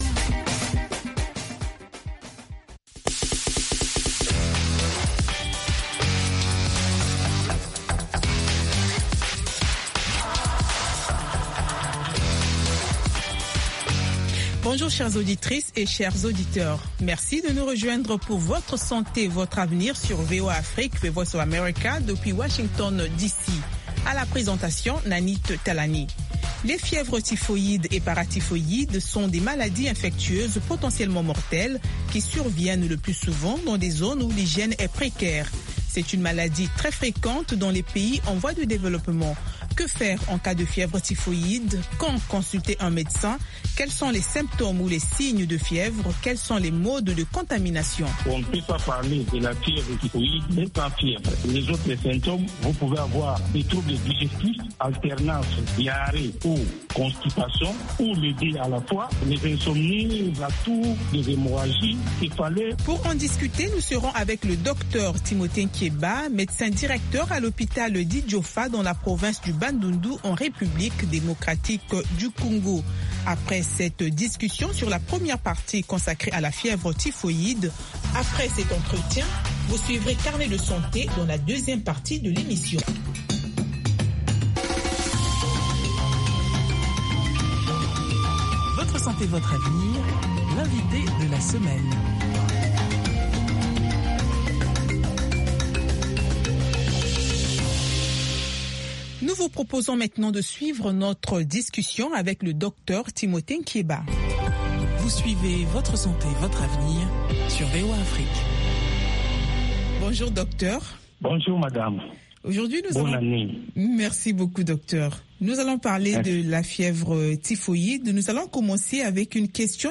Bulletin
5 Min Newscast